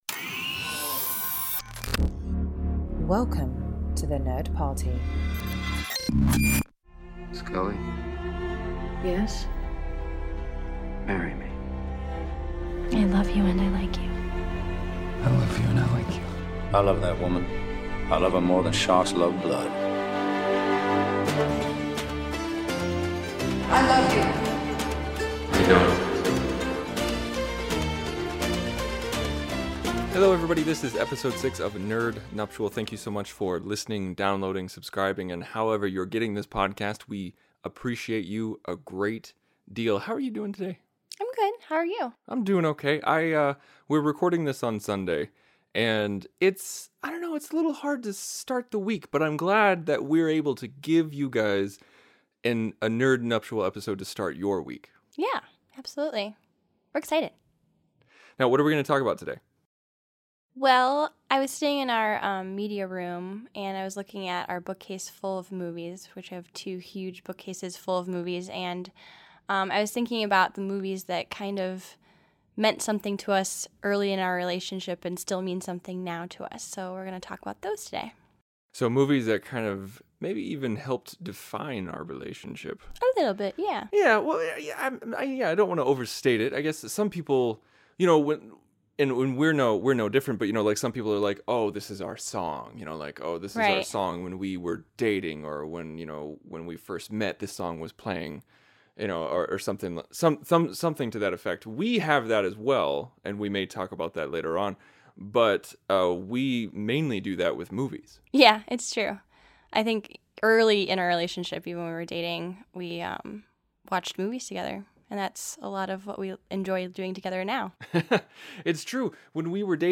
The nerdy couple jump in the Way Back Machine and discuss the origins of their relationship and how certain movies helped shape their experiences.